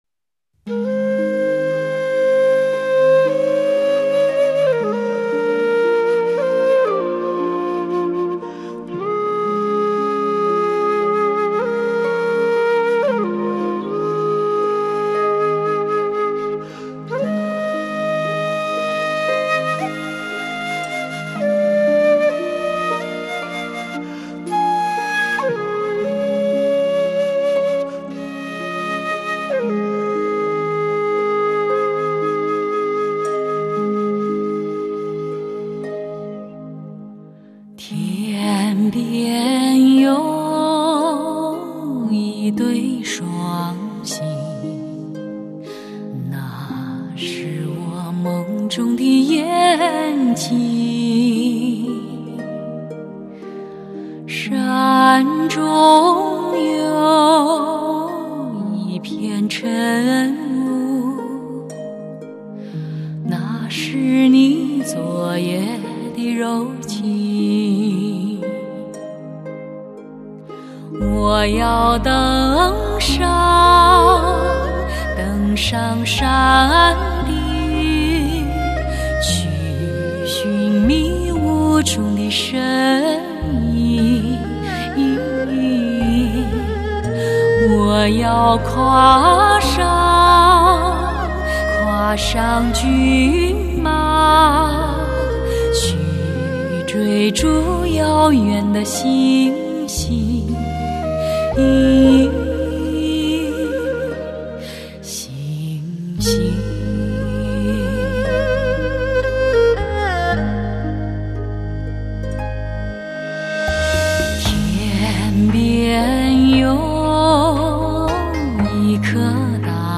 发烧人声
多情自古伤离别，凄美的词风，哀怨的曲韵，
醇美绵长而富有灵性的极品音乐。
母带后期德国精制！